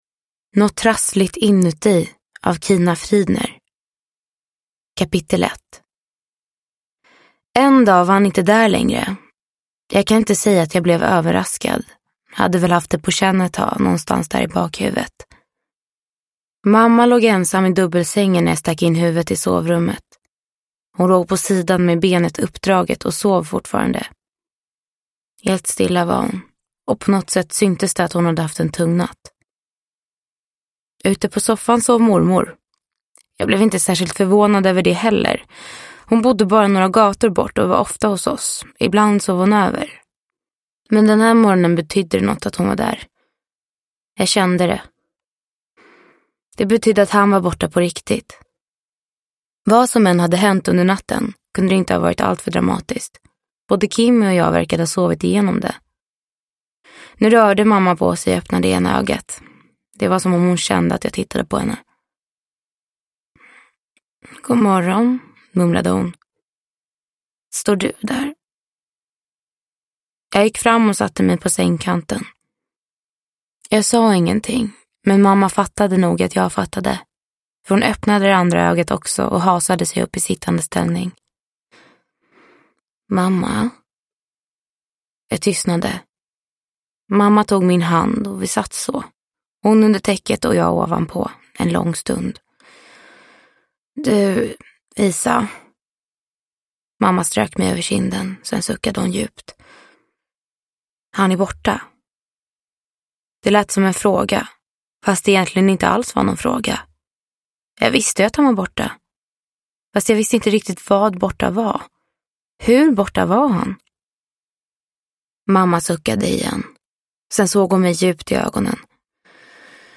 Nåt trassligt inuti – Ljudbok – Laddas ner